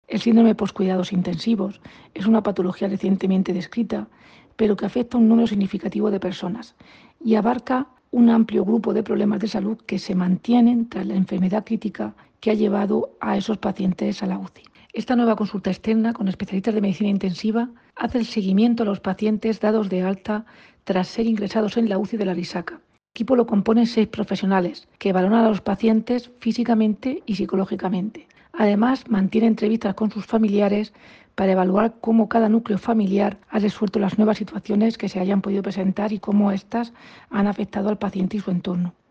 Declaraciones de la directora general de Atención Hospitalaria, Irene Marín, sobre la consulta creada para hacer seguimiento a los pacientes dados de alta de la UCI.